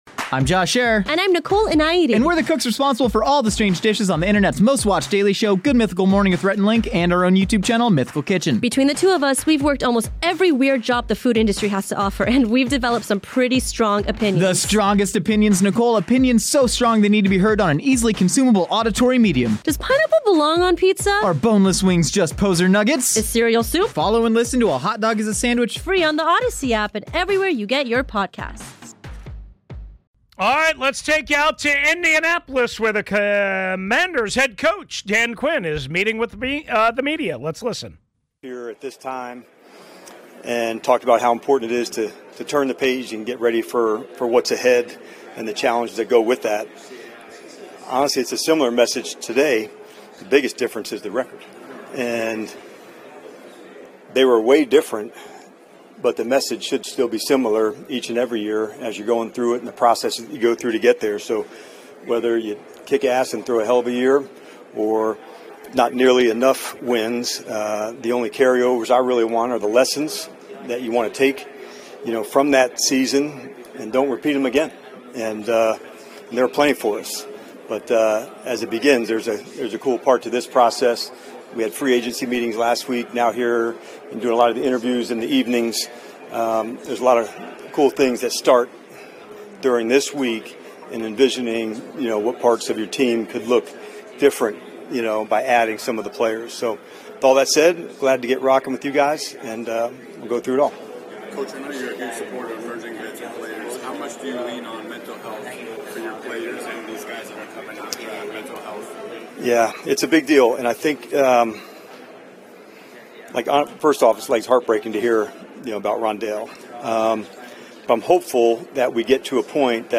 Hour 3 opens with Dan Quinn speaking live to the media from the NFL Combine, providing insight into his expectations for the upcoming season.